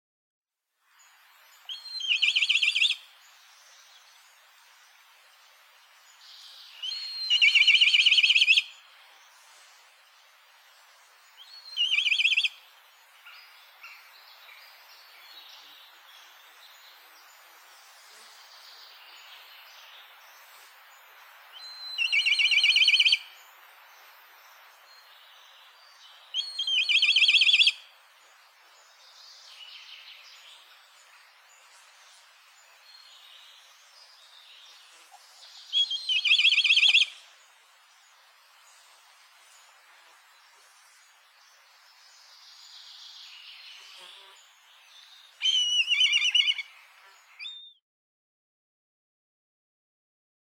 На этой странице собраны разнообразные звуки коршуна – от резких криков до переливчатых трелей.
Звук крика черного коршуна против скворцов